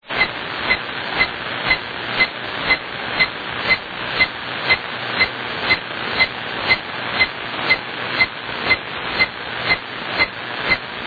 I have a new source of QRM that is audible on 12 meters only. It
were made with a 6kHz filter in AM mode.
At 24.820MHz it sounds like this:
The signal has QSB and, my ham neighbor and I both locate it as coming